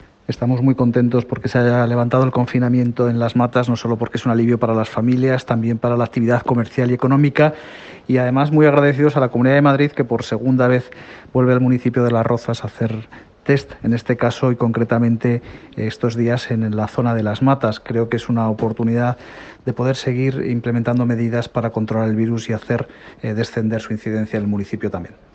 Declaraciones del alcalde de Las Rozas de Madrid, José de la Uz.
Declaraciones José de la Uz_antigenos.m4a